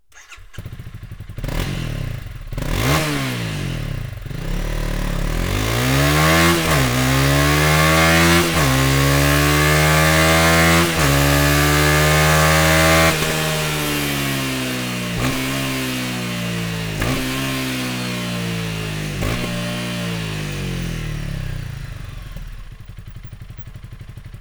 Sound Akrapovic Slip-On